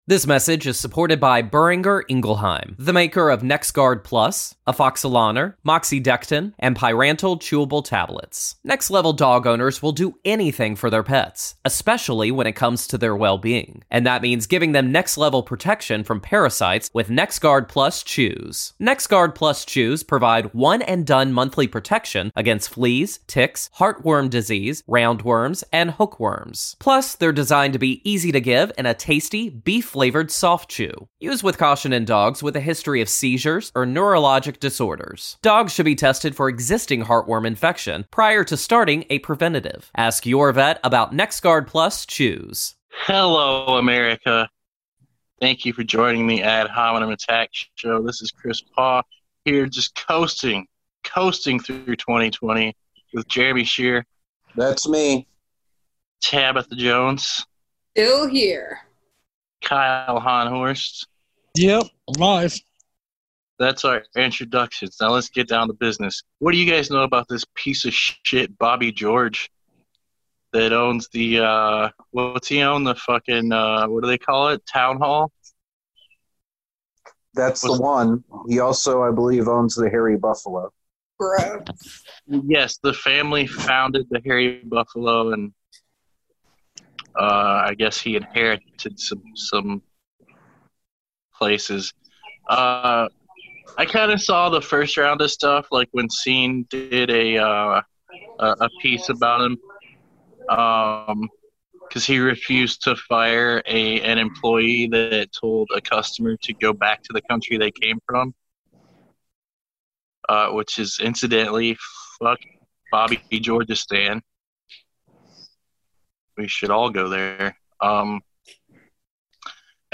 Recorded with Zoom.